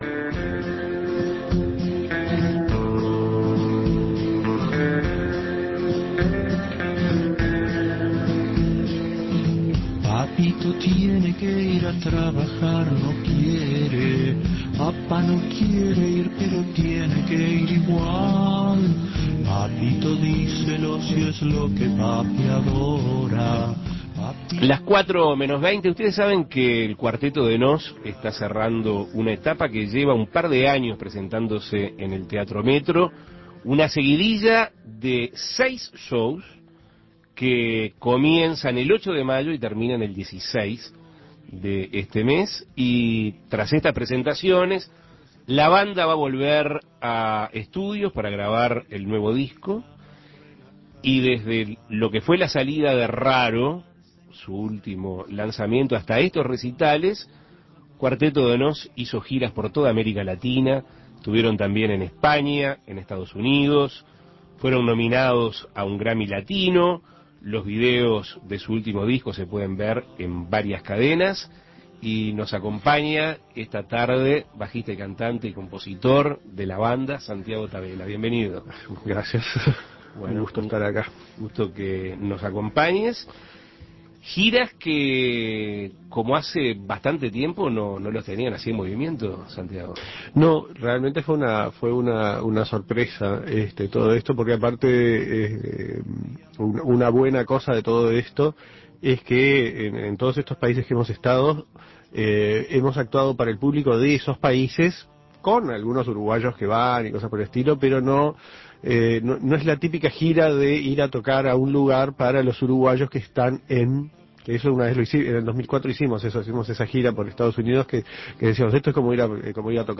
Entrevistas El Cuarteto de Nos cerrará una nueva etapa Imprimir A- A A+ El Cuarteto de Nos está cerrando una etapa de dos años.